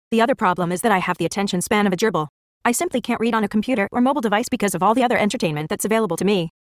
Now when we go back to the text we’ve been using for our testing, instead of using a giant pulldown like an animal, we can select the text and then simply hit option-escape to hear it read in the dulcet tones of Siri Female (US):
Now it sounds good! Maybe a tad fast for a technical paper but I’ve found that a faster voice seems to force me to pay attention better.
gerbil_Siri_voice_fast.mp3